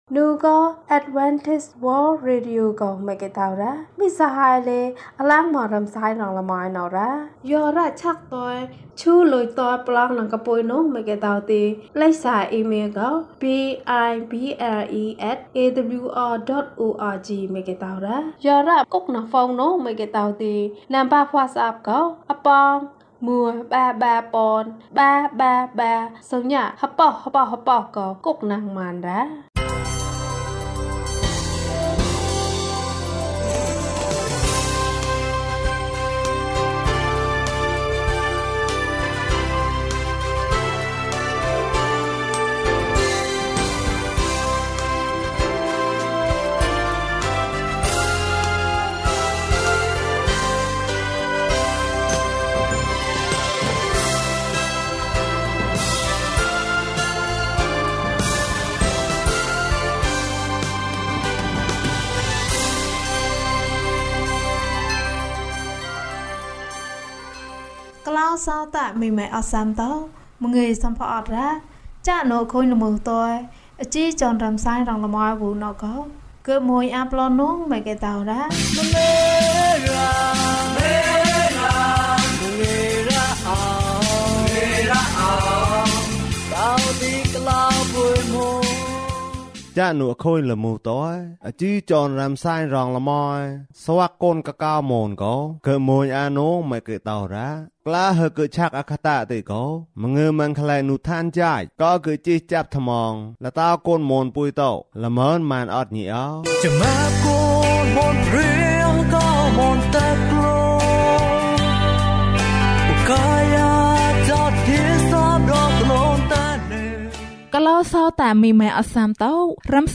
သူ့နာမည်။ ကျန်းမာခြင်းအကြောင်းအရာ။ ဓမ္မသီချင်း။ တရားဒေသနာ။